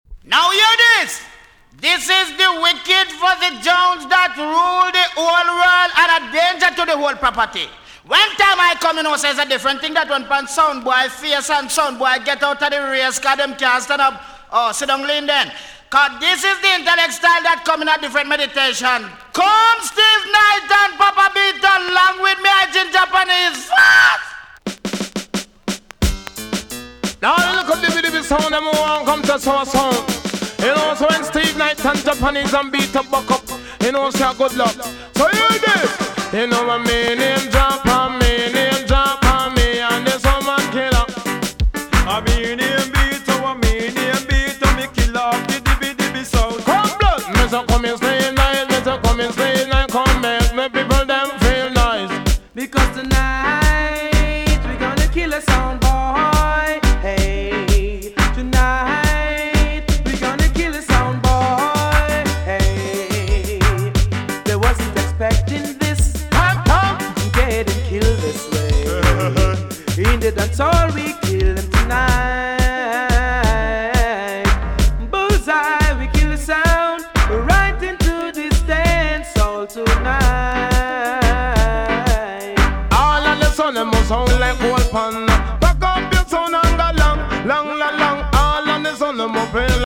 TOP >80'S 90'S DANCEHALL
EX- 音はキレイです。